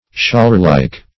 Scholarlike \Schol"ar*like`\, a.